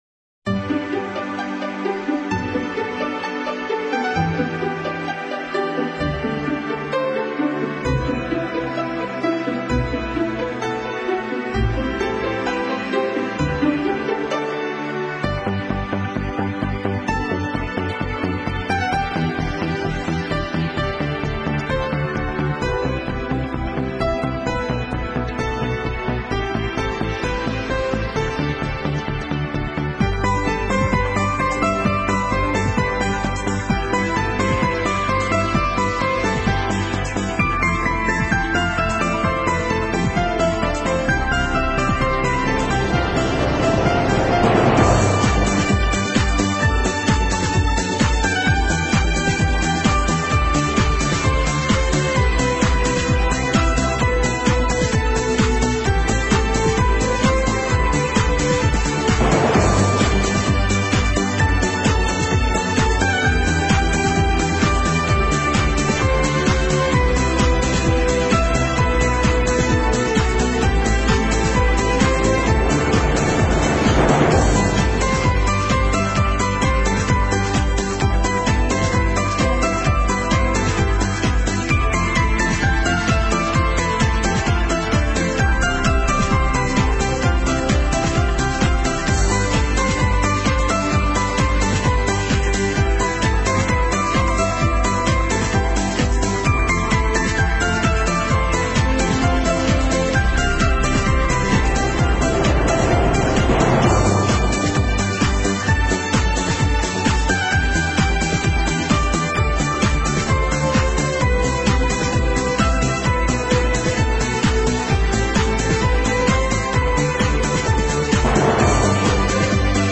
【轻音乐专辑】
音乐风格：  其他|古典|Neo Classical，室内乐